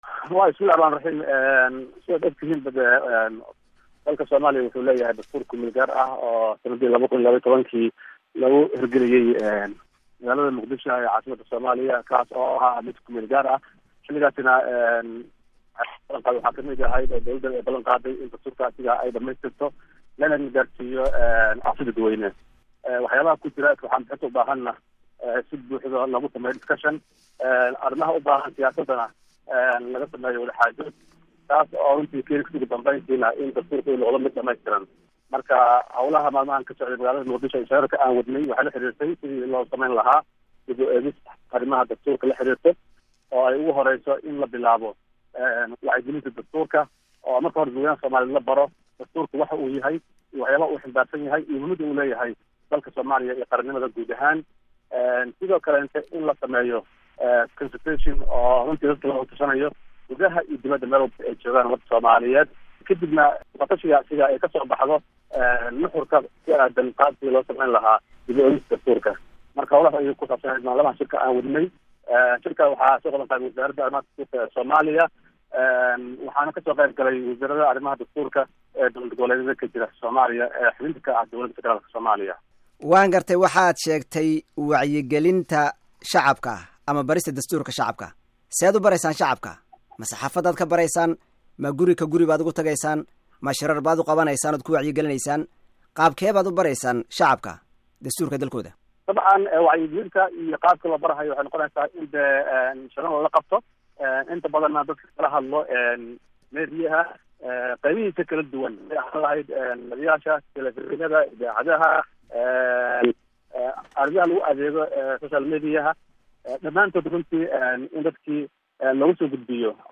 waraysi VOA la yeelatay Wasiirul dawlaha Dastuurka